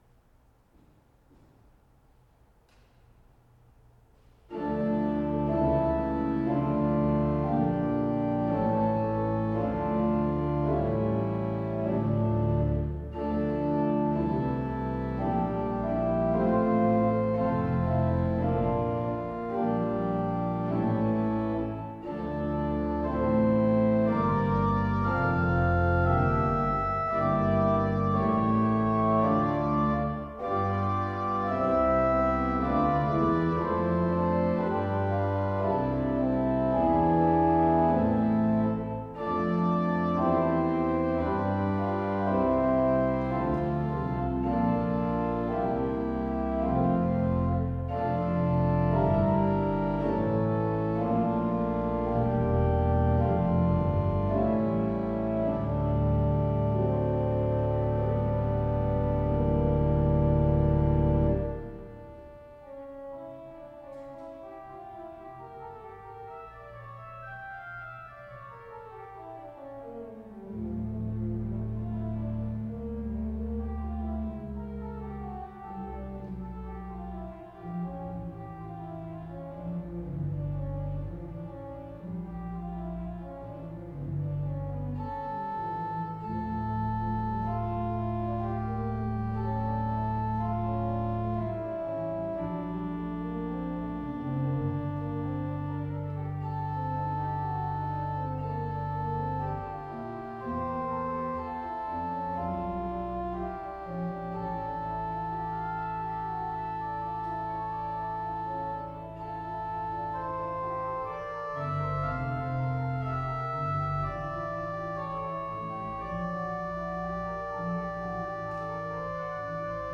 Recordings of the Organ